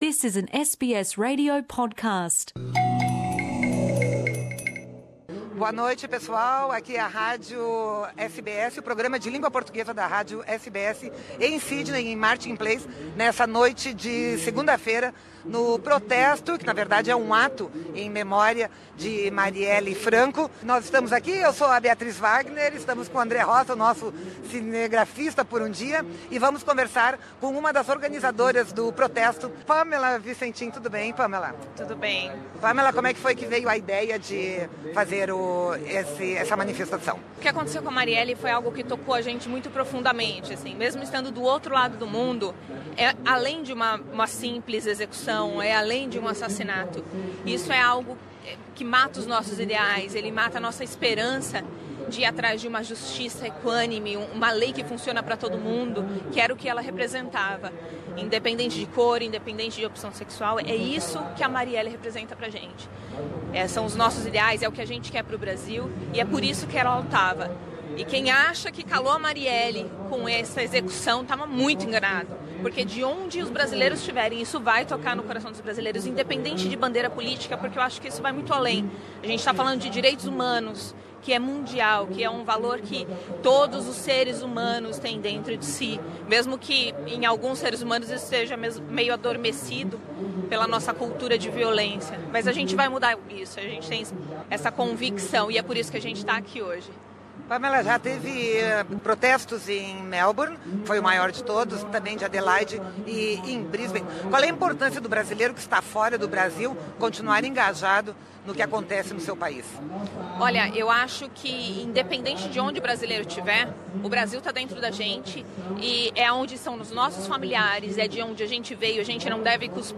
Protesto por Marielle em Sydney: manifestantes pedem por justiça e fim da violência